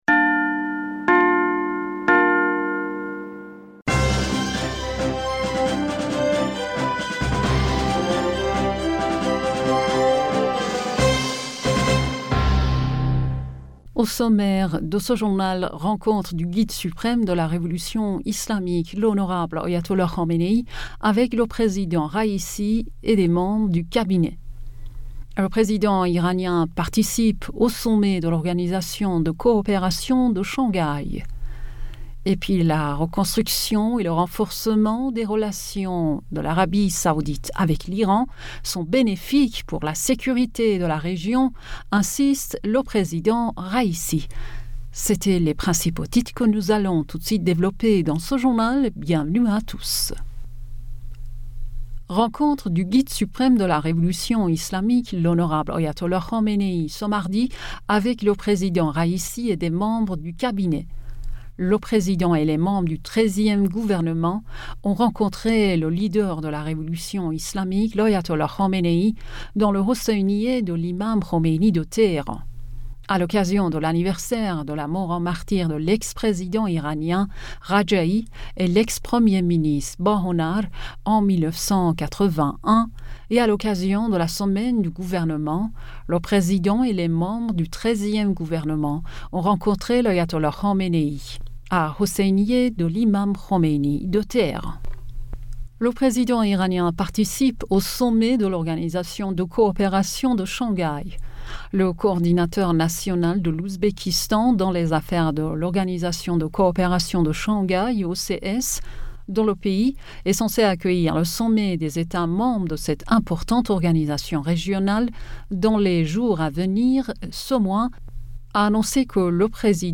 Bulletin d'information Du 30 Aoùt